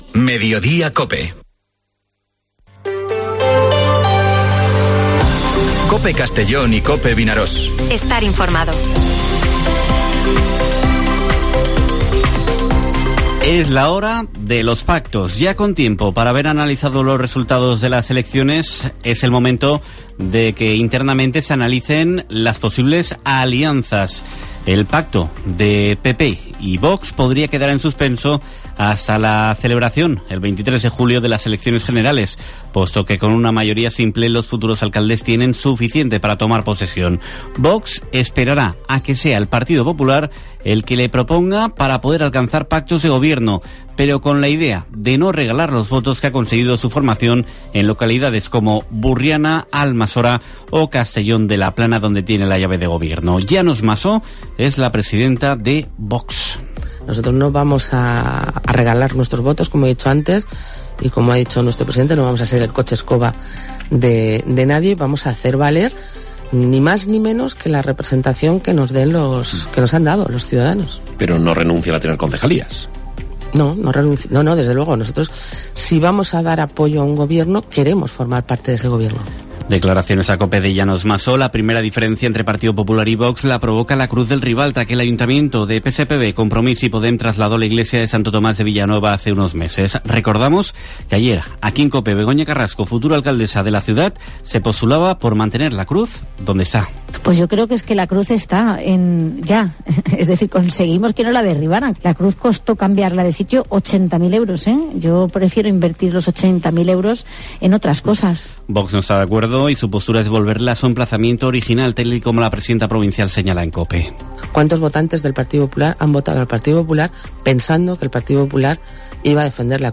Informativo Mediodía COPE en la provincia de Castellón (30/05/2023)